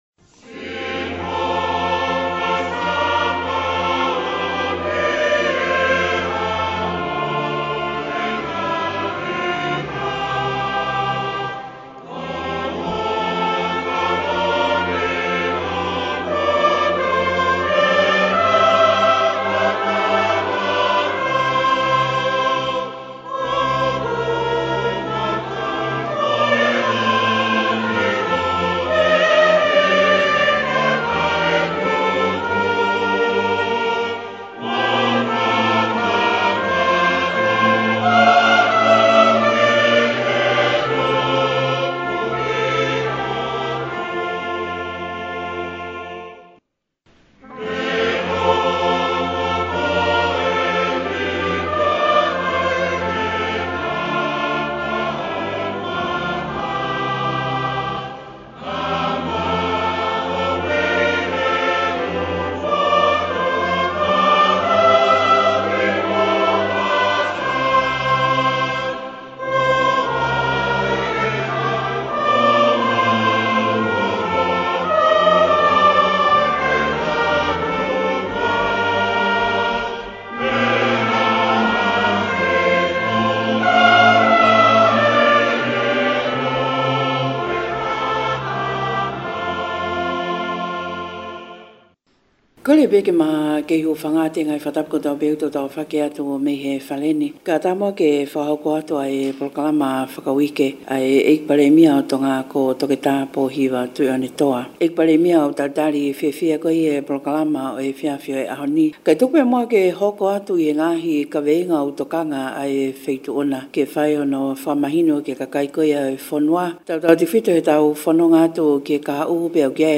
Prime Minister Pōhiva Tu’i’onetoa was interviewed by FM87.5 on January 16.
For our Tongan readers here is the full interview audio in Tongan Language